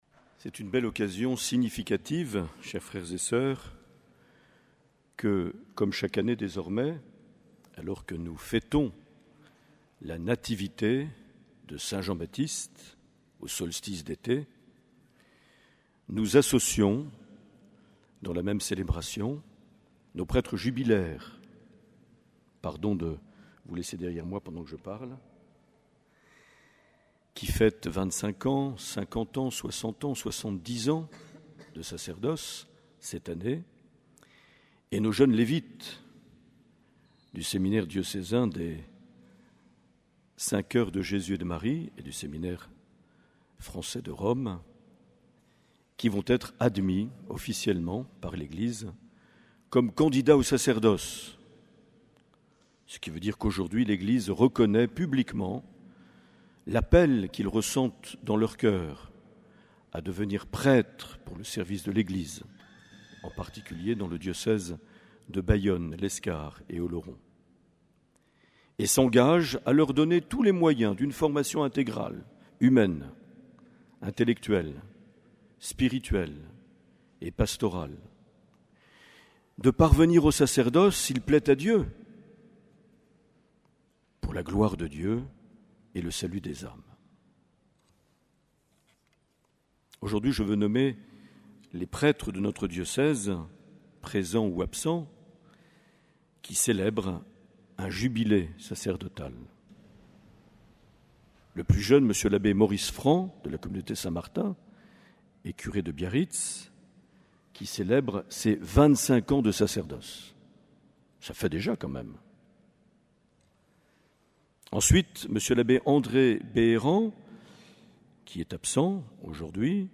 24 juin 2017 - Cathédrale de Bayonne - Messe avec les prêtres jubilaires et admission des candidats au sacerdoce
Une émission présentée par Monseigneur Marc Aillet